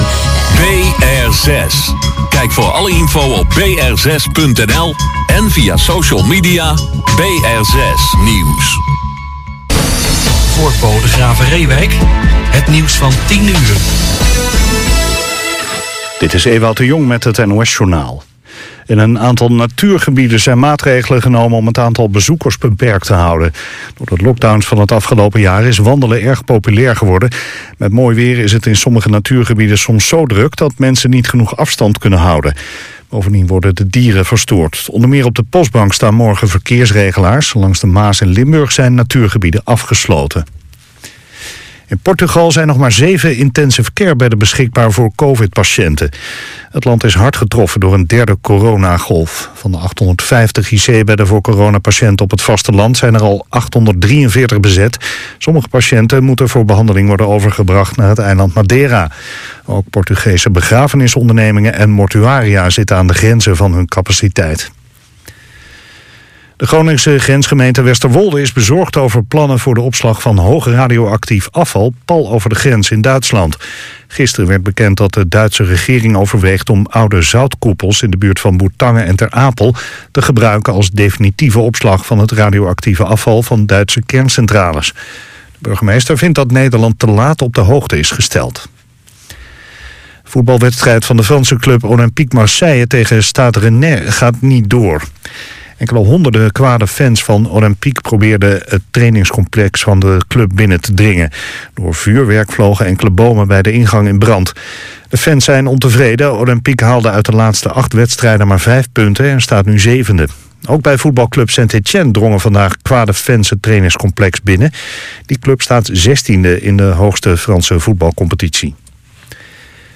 Gitaar
Bass
Drums
Zang
Piano
Tenor-sax
Trompet
Trombone